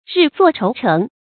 日坐愁城 注音： ㄖㄧˋ ㄗㄨㄛˋ ㄔㄡˊ ㄔㄥˊ 讀音讀法： 意思解釋： 愁城：比喻為憂愁所包圍。